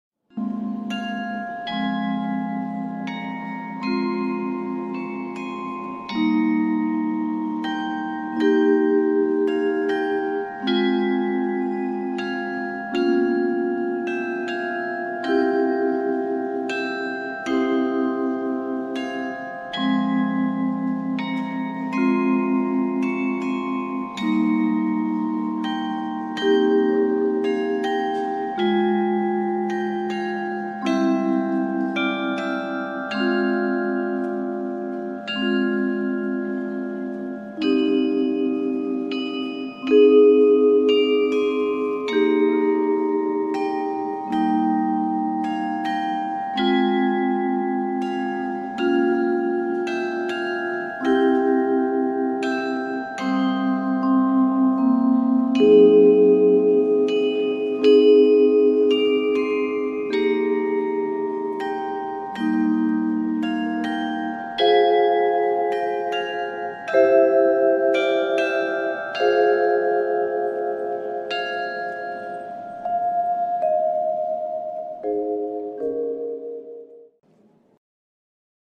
実際の音色
実際の演奏で使った時の音源です。
被災地支援企画『遠い日にしない、あの時』で演奏しました。
そのためか演奏クオリティは低いです・・・
未熟な演奏ですが、カリヨンとビブラフォンの演奏は下記より視聴できます。